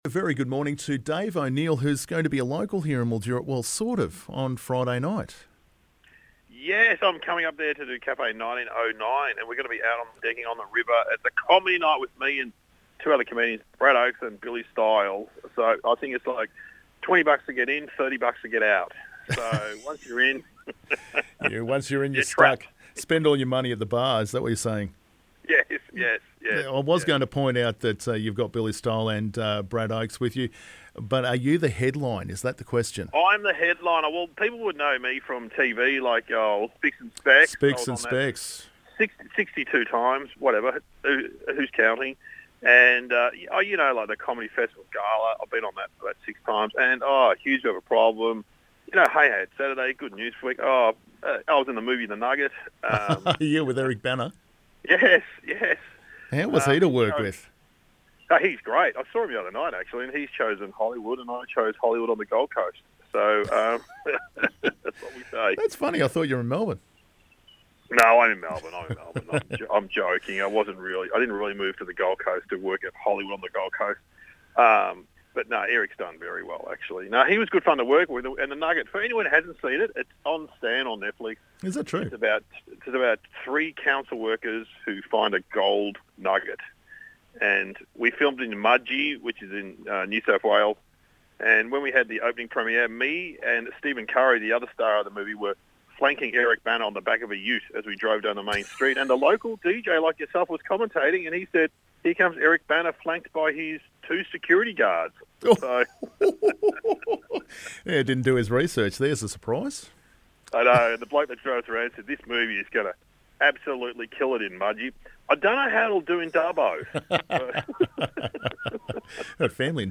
You can tell that the conversation isn't scripted.